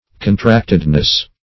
Search Result for " contractedness" : The Collaborative International Dictionary of English v.0.48: Contractedness \Con*tract"ed*ness\, n. The state of being contracted; narrowness; meanness; selfishness.
contractedness.mp3